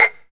PIZZ VLN C6.wav